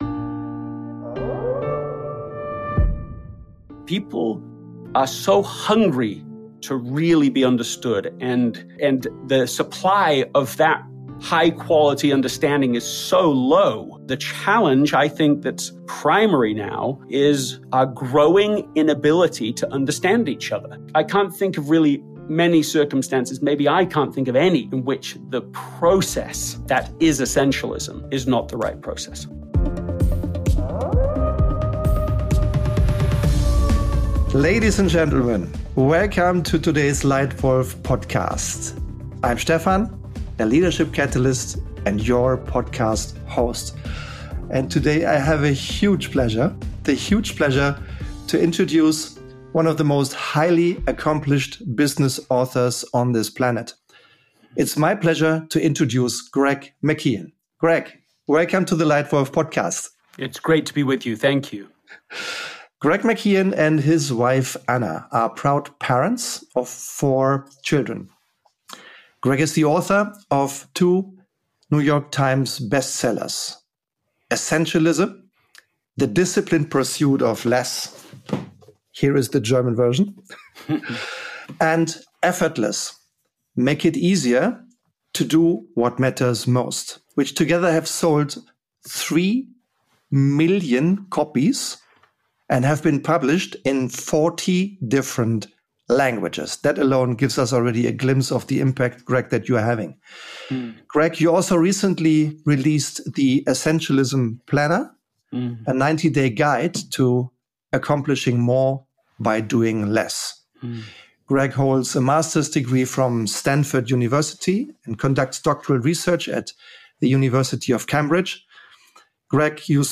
Beschreibung vor 11 Monaten Essentialism: Doing Less but Better – A LEITWOLF Interview with Greg McKeown What if doing less could actually lead to more impact?